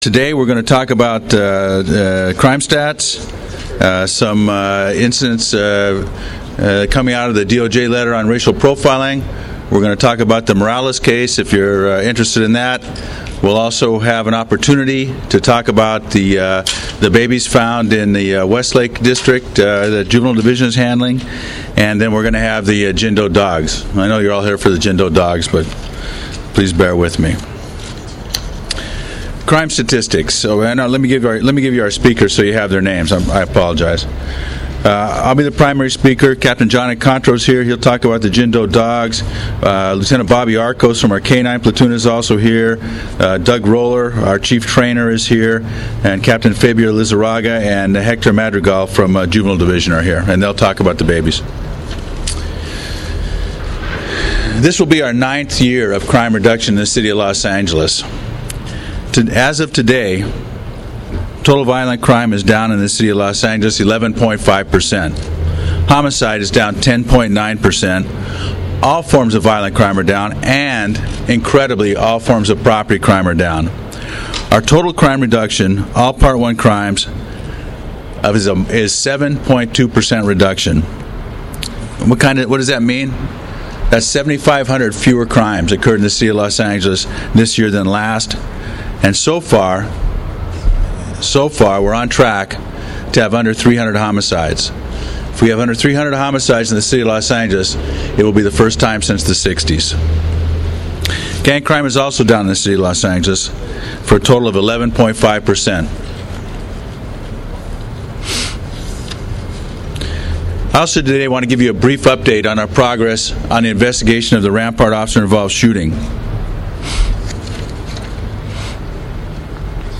Tuesday, November 16, 2024 – Chief Beck held his monthly media availability in the front plaza of the LAPD Administration Building. He holds this news conference once a month to discuss different topics with the media.